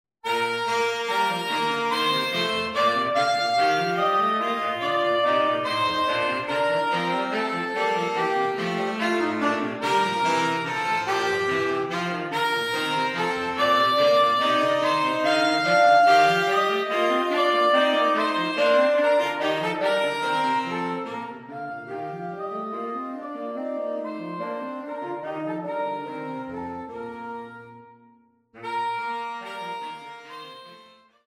These flexible pieces can be played as either Duets or Trios
Eb Alto and/or Bb Tenor Saxophone
Eb Baritone Saxophone and/or Bassoon